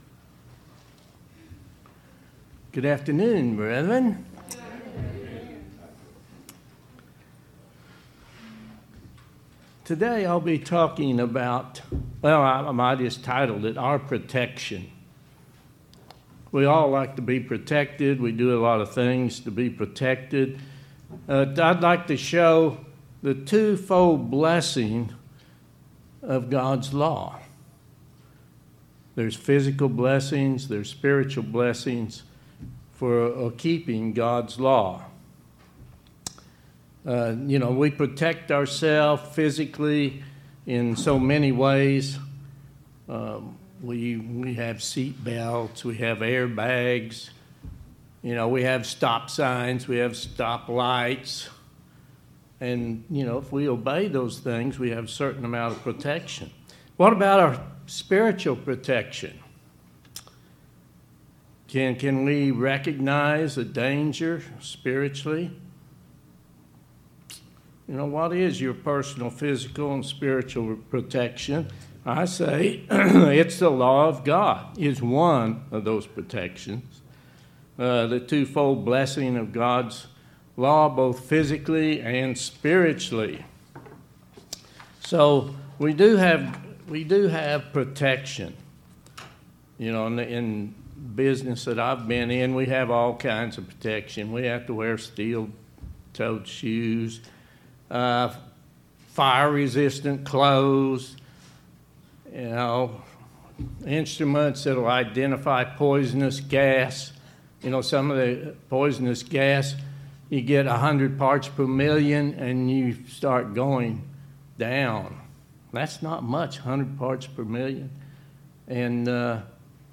Sermons
Given in Oklahoma City, OK